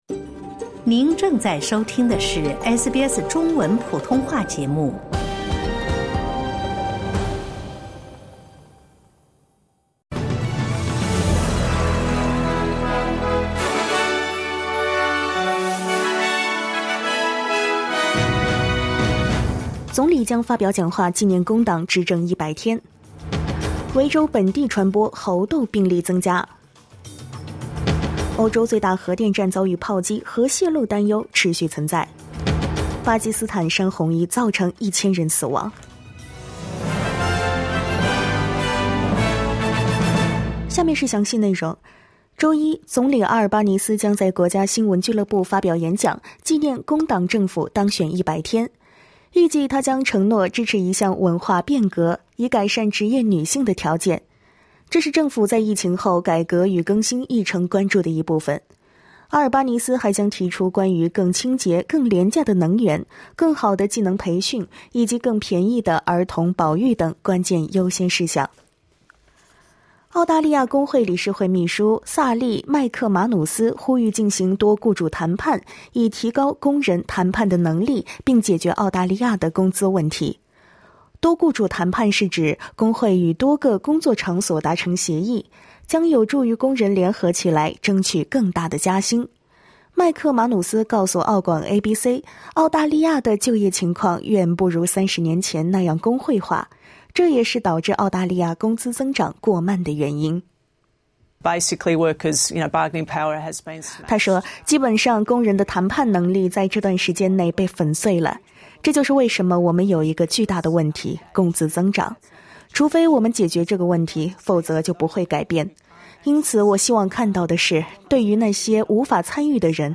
SBS早新闻（8月29日）
请点击收听SBS普通话为您带来的最新新闻内容。